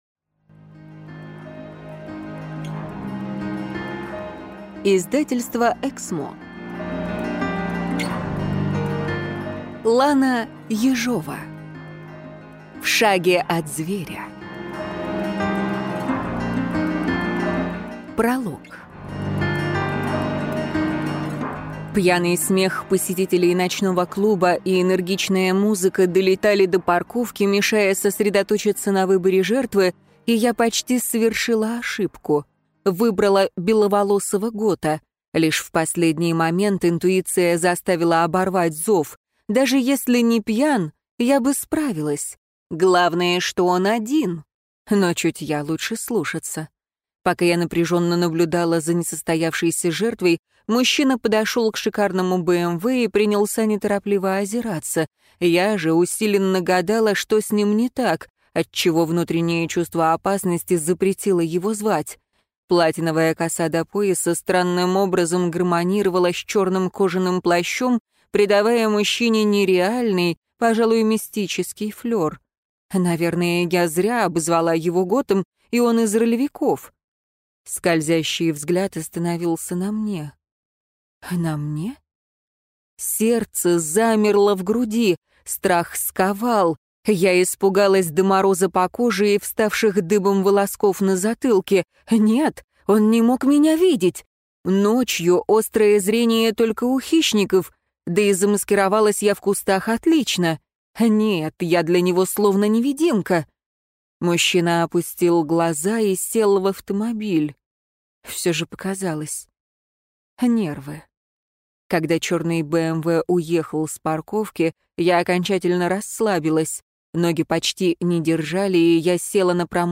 Аудиокнига В шаге от зверя | Библиотека аудиокниг